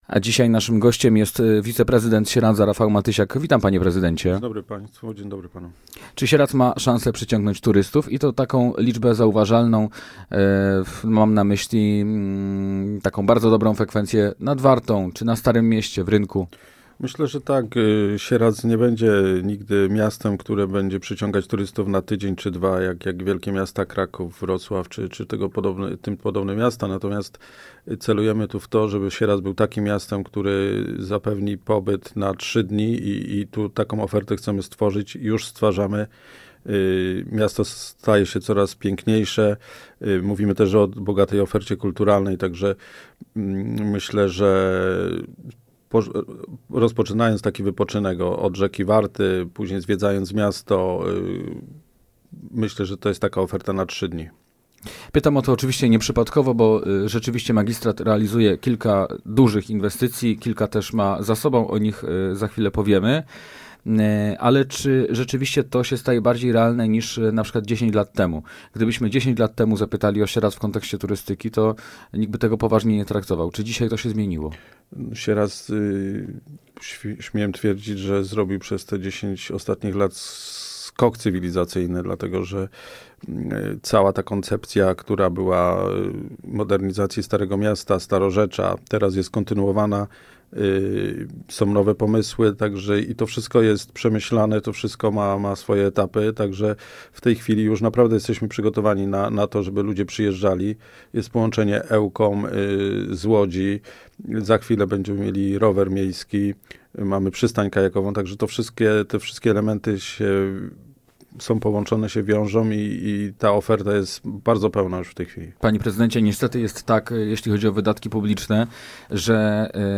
Gościem Radia Łódź Nad Wartą był wiceprezydent Sieradza, Rafał Matysiak.
Posłuchaj całej rozmowy: Nazwa Plik Autor – brak tytułu – audio (m4a) audio (oga) Warto przeczytać Fly Fest 2025.